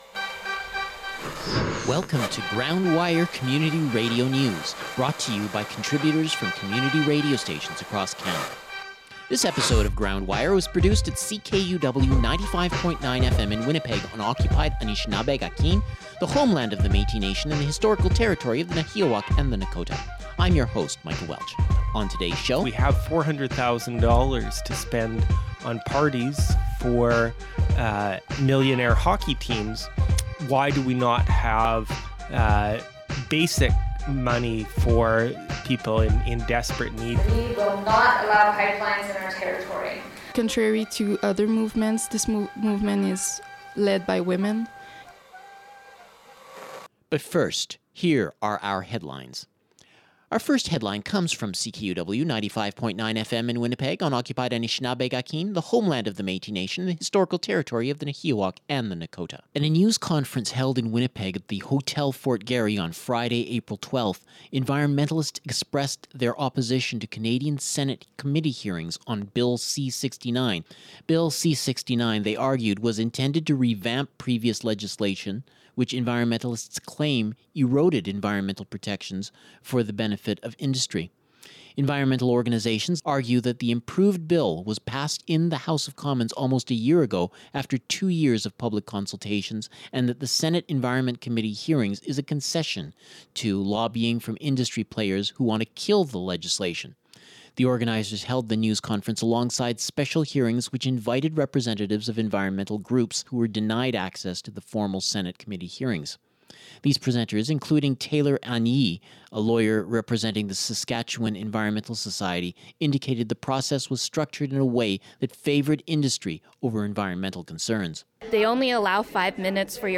Community radio news from coast to coast